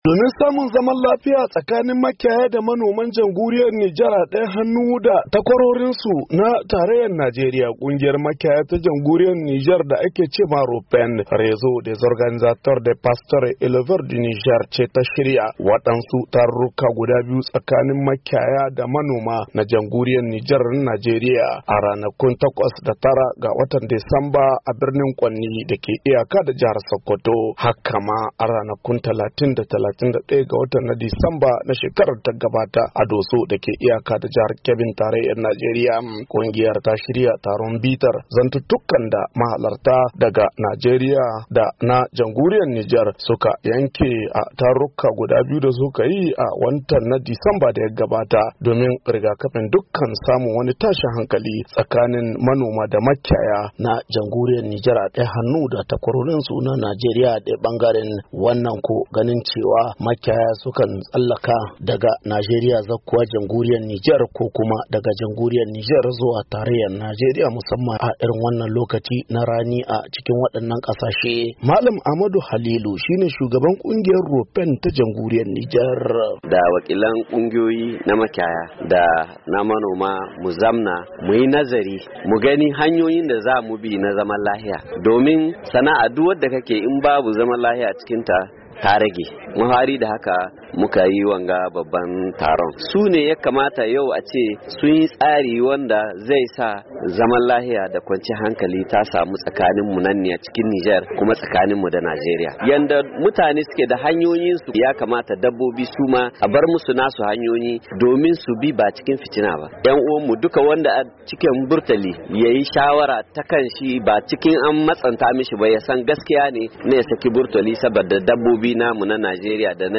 Ga rahoton